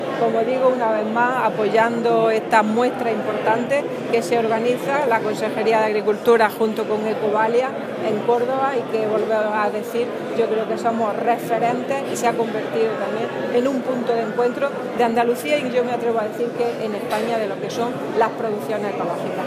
Declaraciones Carmen Ortiz sobre BioCórdoba (2)